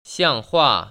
[xiàng//huà] 시앙후아